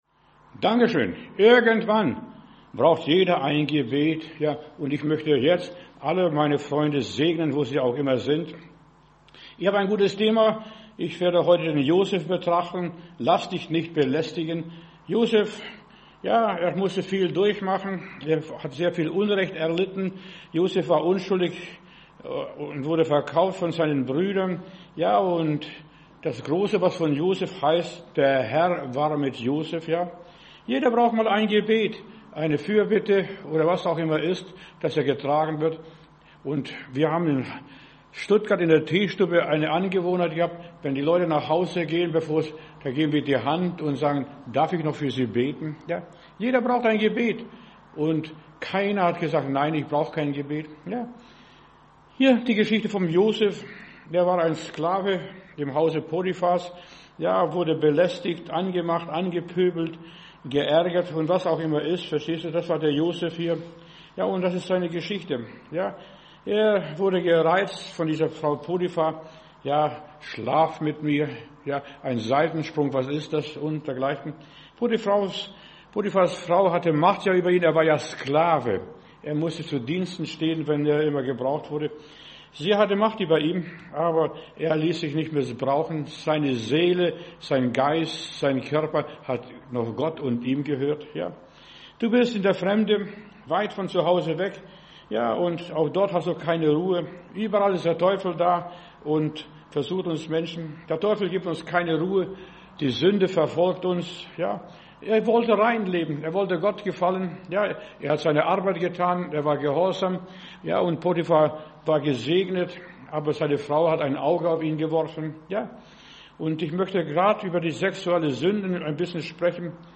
Predigt herunterladen: Audio 2025-11-26 Lass dich nicht belästigen Video Lass dich nicht belästigen